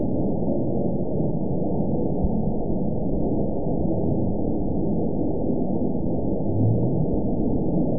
event 920924 date 04/16/24 time 06:39:48 GMT (1 year ago) score 9.11 location TSS-AB04 detected by nrw target species NRW annotations +NRW Spectrogram: Frequency (kHz) vs. Time (s) audio not available .wav